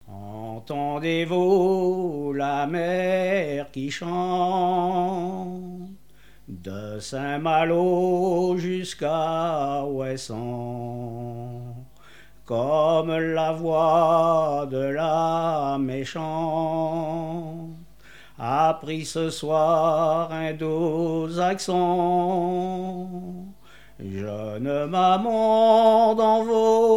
Genre strophique
chansons maritimes contemporaines
Catégorie Pièce musicale inédite